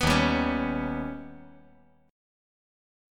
Eb+7 Chord
Listen to Eb+7 strummed